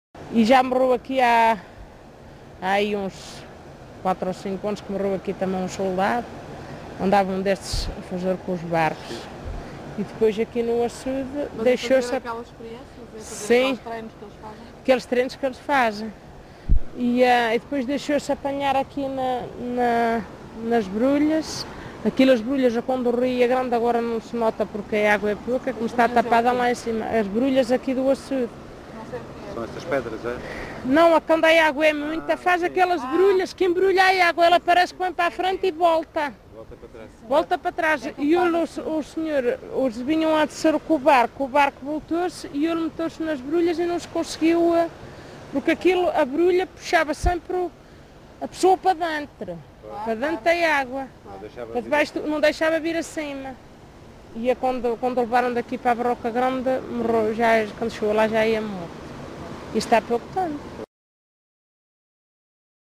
LocalidadePorto de Vacas (Pampilhosa da Serra, Coimbra)